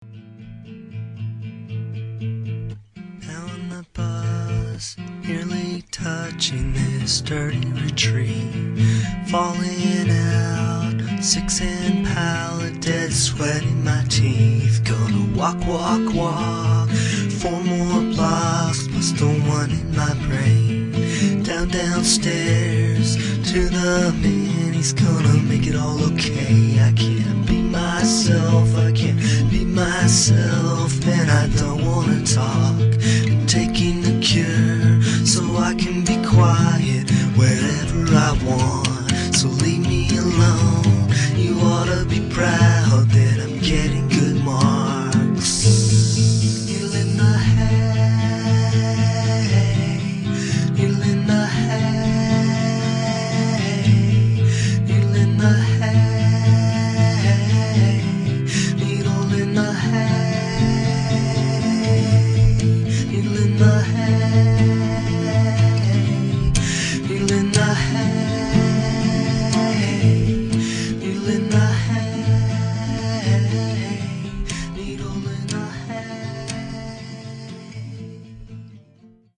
La solitudine, soprattutto la solitudine.